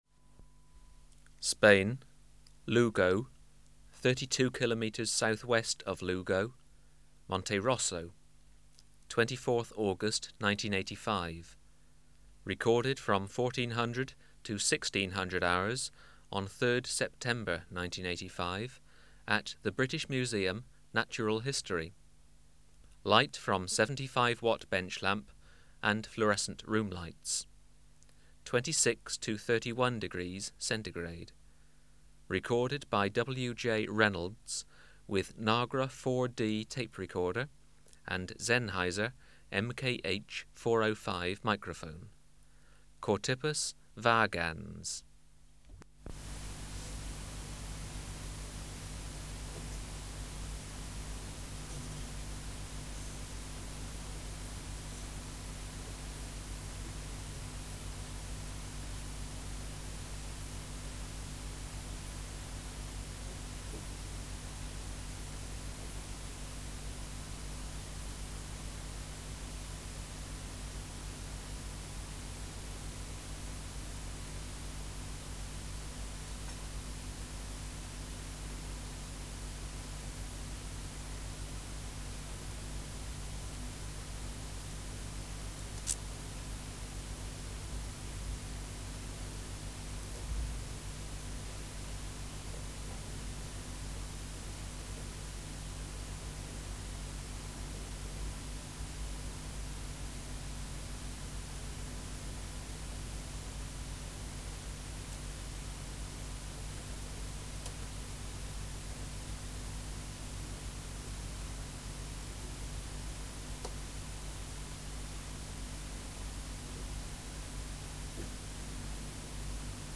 Recording Location: BMNH Acoustic Laboratory
Reference Signal: 1 kHz for 10 s
Substrate/Cage: Small recording cage
Microphone & Power Supply: Sennheiser MKH 405 Filter: Low Pass, 24 dB per octave, corner frequency 20 kHz